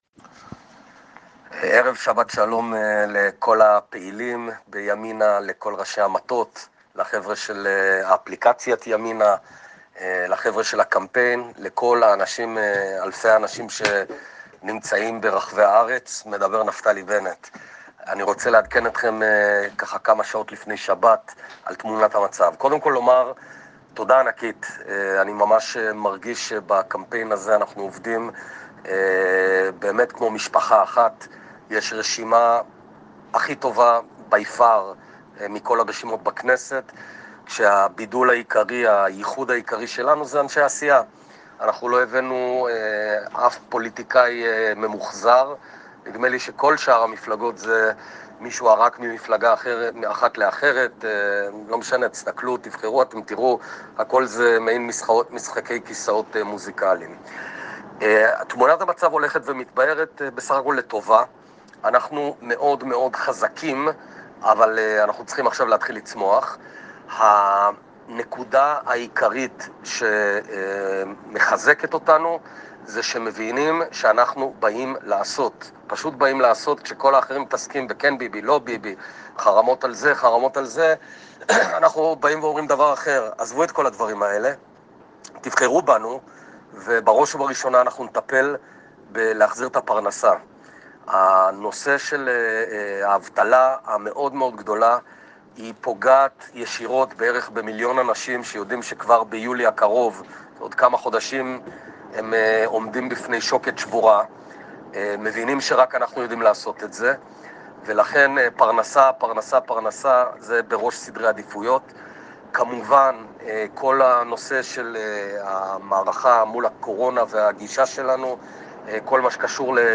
את ההקלטה הקולית, שהגיעה לסרוגים, שלח נפתלי בנט לפעילי ימינה עם עדכונים על הקמפיין: "אני רוצה לעדכן אתכם, כמה שעות לפני שבת, על תמונת המצב: קודם כל, תודה ענקית.
כך זה נשמע בקולו של בנט: